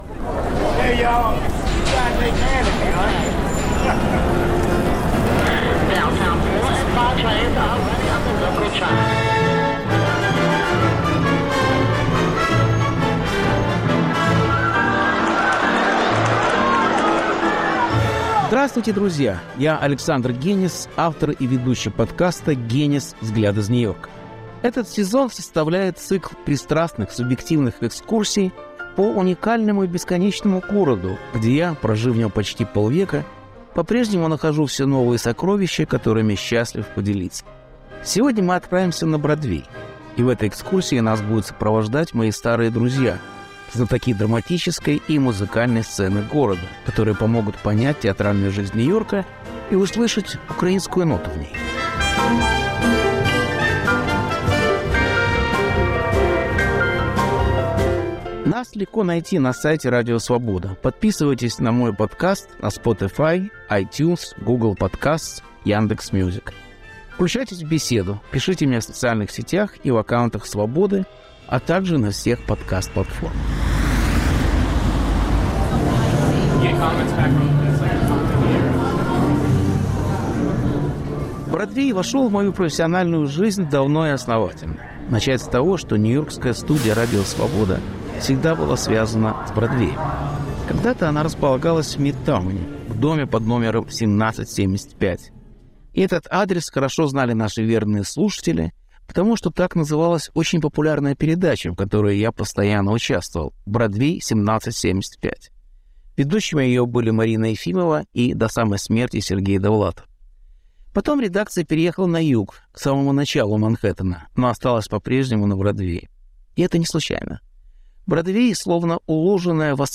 Повтор эфира от 2 апреля 2023 года.